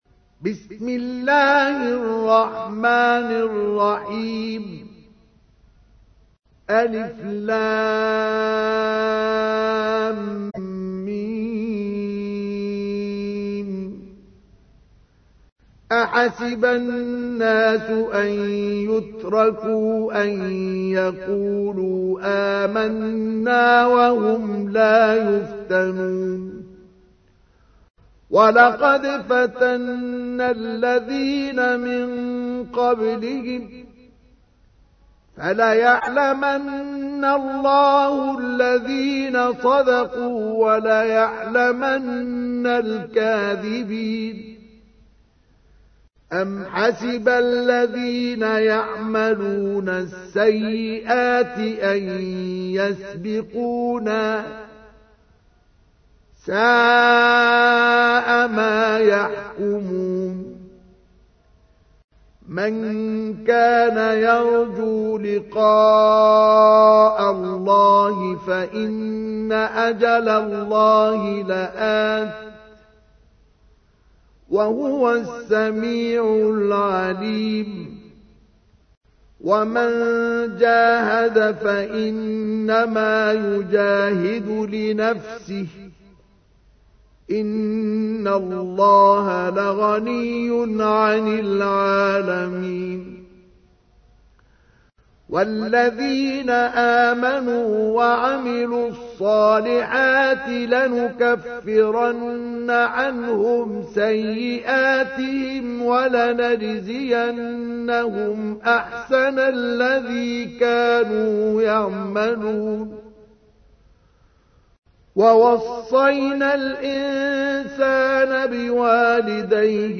تحميل : 29. سورة العنكبوت / القارئ مصطفى اسماعيل / القرآن الكريم / موقع يا حسين